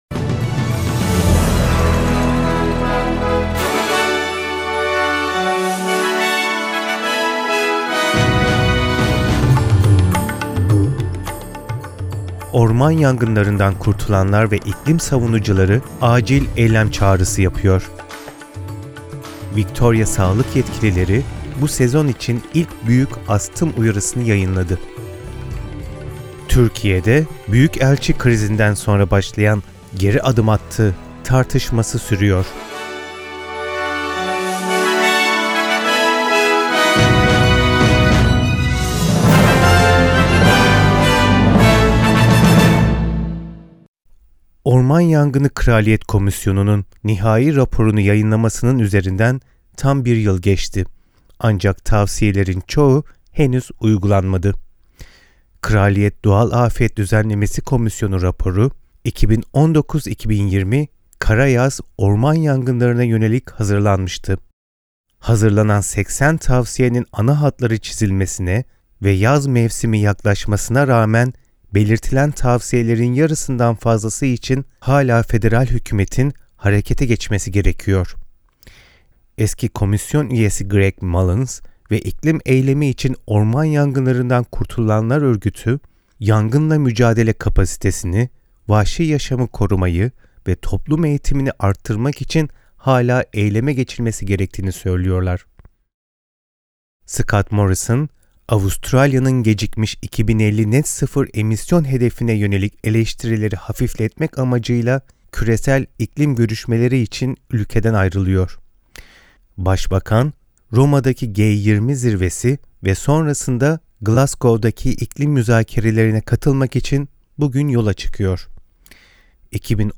SBS Türkçe'den Avustralya, Türkiye ve dünya haberleri.